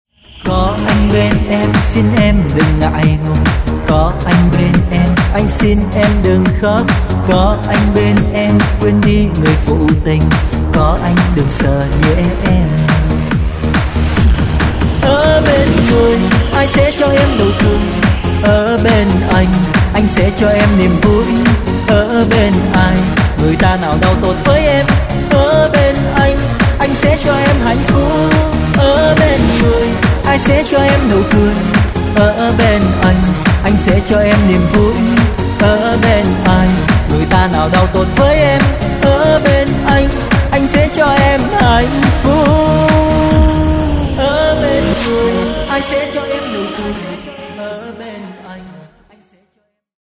Pop/ Acoustic/ Indie